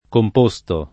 composto [ komp 1S to ]